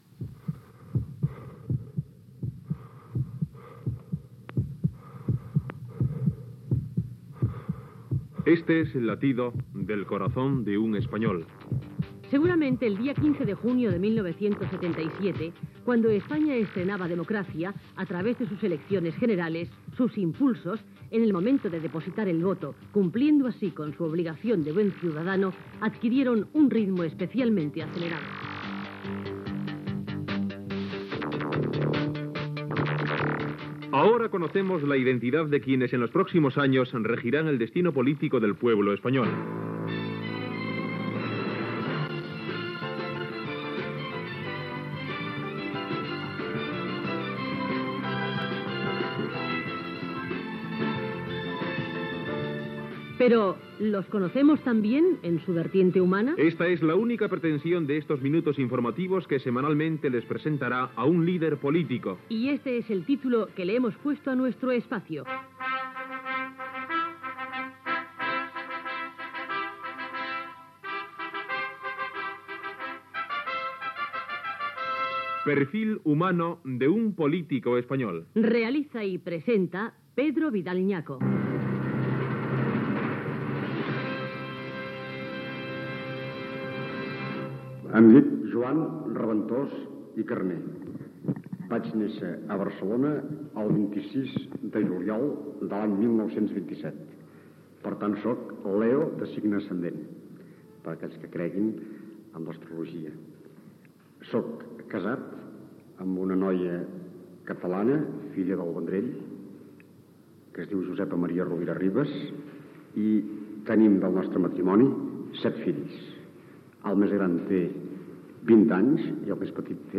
Presentació del programa, autopresentació feta pel polític Joan Raventós
Entreteniment